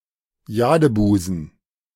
The Jade Bight[1][2] (also known as Jade Bay;[3] German: Jadebusen, pronounced [ˈjaːdəˌbuːzn̩]